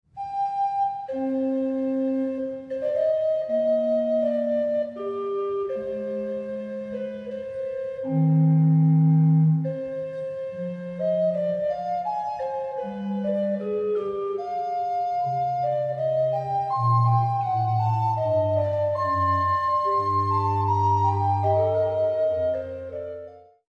Garnisons Kirke, Copenhagen